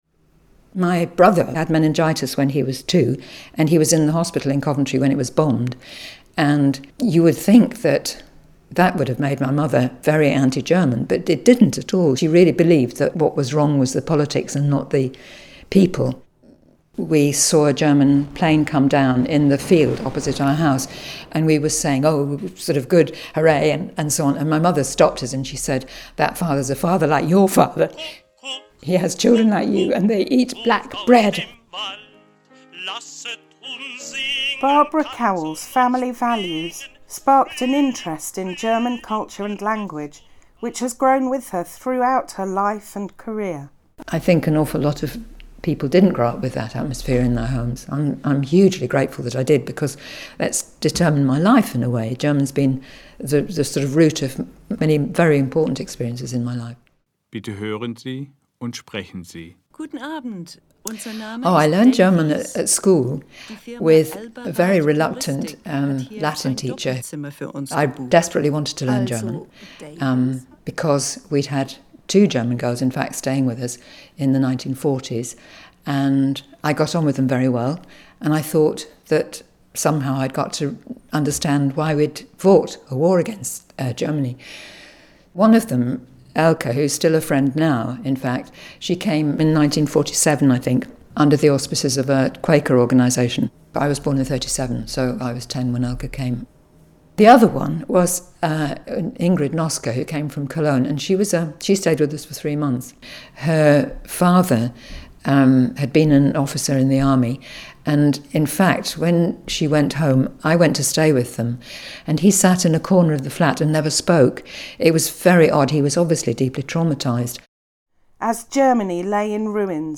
Reading